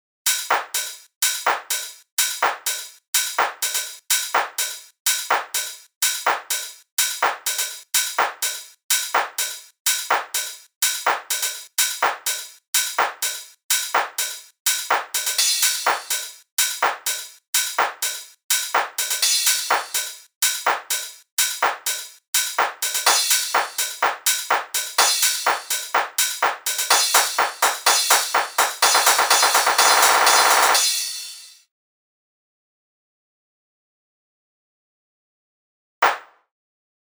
Intro/Build 1:
Here is a bounce of the drums depicted here, with no FX or automation:
No-FX-Auto.mp3